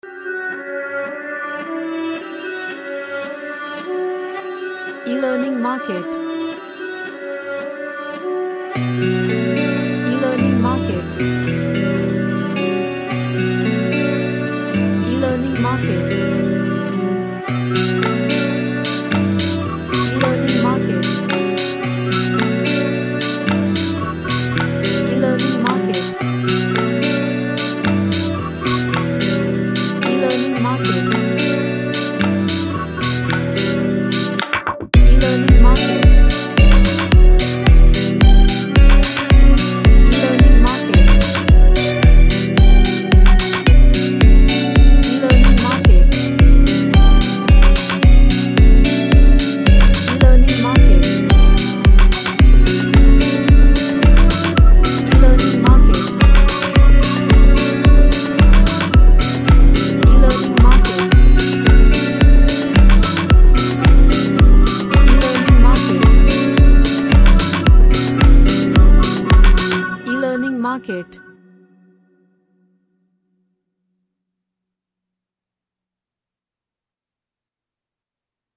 A house track with futuristic drums
Sci-Fi / Future